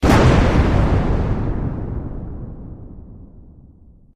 Explosion1.ogg